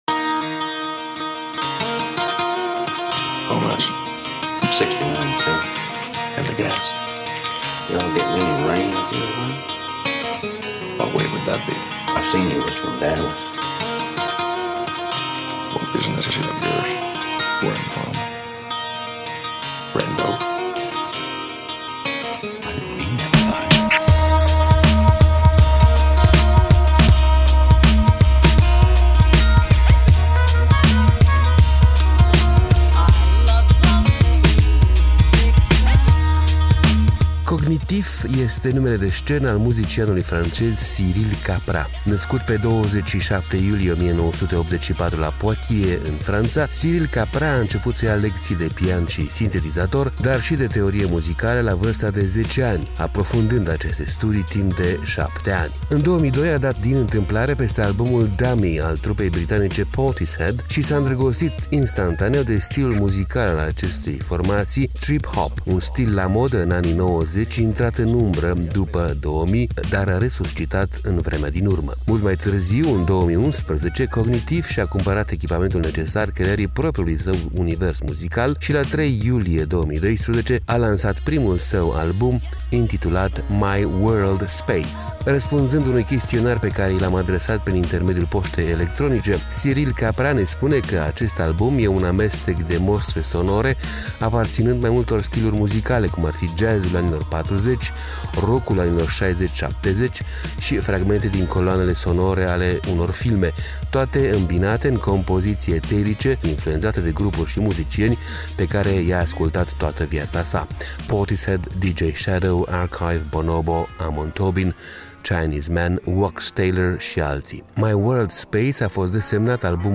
În 2002 a dat din întîmplare peste albumul „Dummy”, al trupei britanice Portishead, și s-a îndrăgostit instantaneu de stilul muzical al acestei formații, trip-hop, un stil la modă în anii '90, intrat în umbră după 2000, dar resuscitat în vremea din urmă.
un amestec de mostre sonore aparținînd mai multor stiluri muzicale, cum ar fi jazz-ul anilor '40, rock-ul anilor '60 - '70, și fragmente din coloanele sonore ale unpor filme, toate îmbinate în compoziții eterice care folosesc sunetele sintetizate